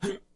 伤害的声音 " 男性伤害3
标签： 咕噜 视频 医疗 死亡 游戏 伤害 伤害 疼痛
声道立体声